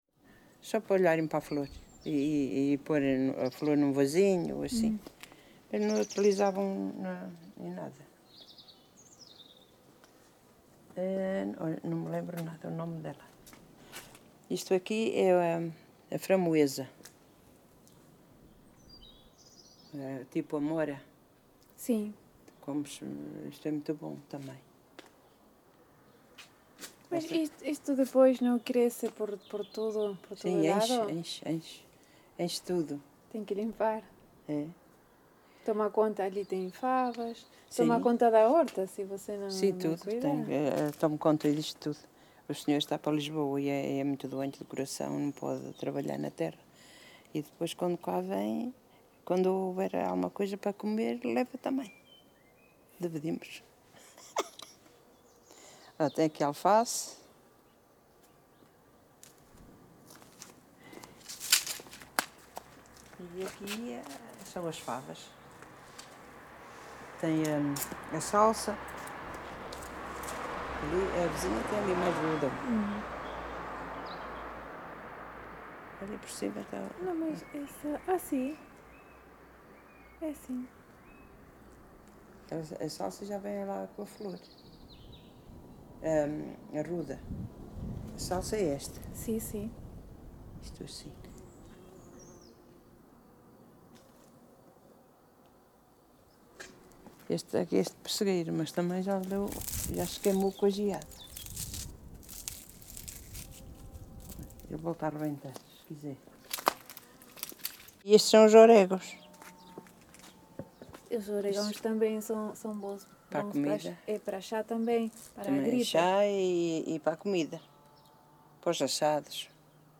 Várzea de Calde, primavera de 2019. Registo sonoro integrado numa série de gravações realizadas para o projeto Viseu Rural 2.0 e para o Arquivo da Memória de Várzea de Calde, cujo tema principal são diversos usos e conhecimentos sobre plantas: medicina, culinária, construção de ferramentas...
Tipo de Prática: Inquérito Oral